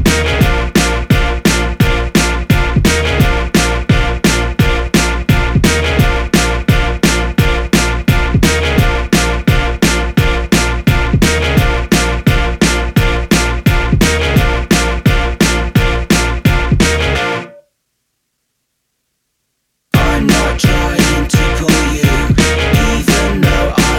no spoken BV Pop (2000s) 4:12 Buy £1.50